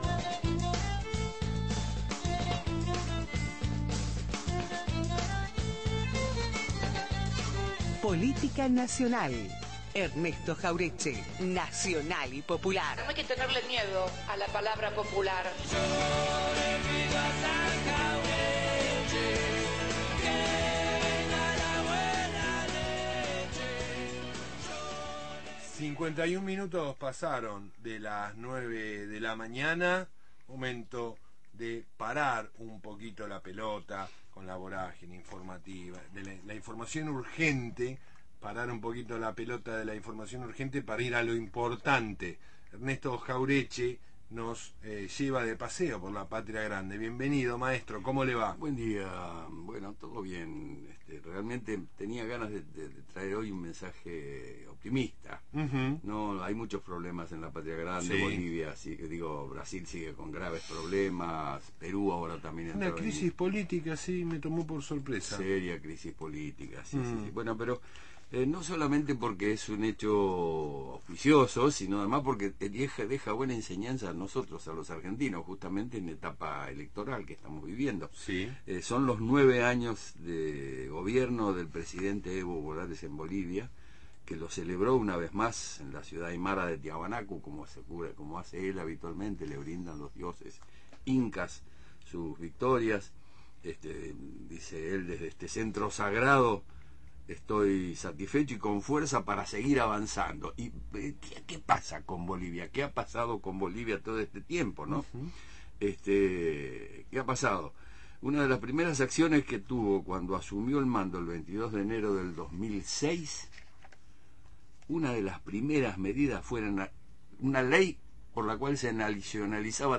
columna de política nacional en «Mañana 1390»